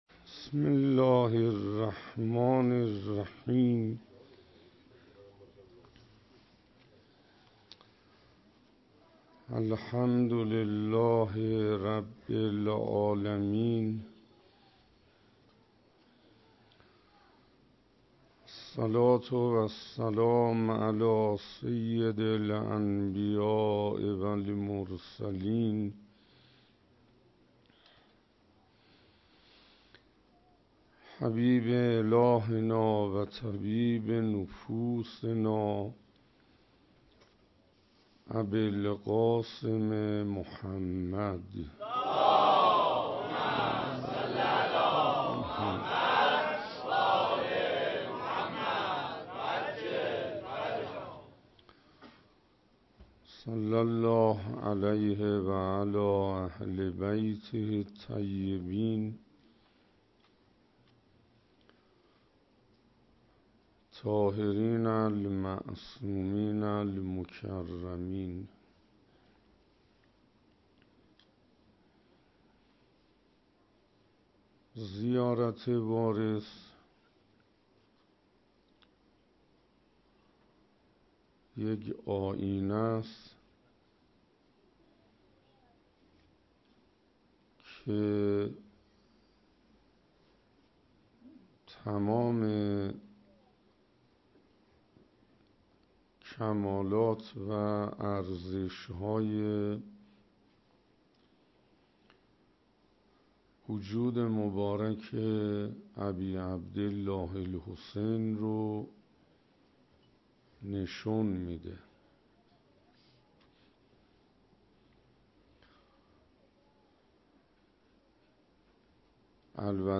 شب ششم محرم 96 - حسینیه حضرت ابالفضل علیه السلام (تهرانپارس)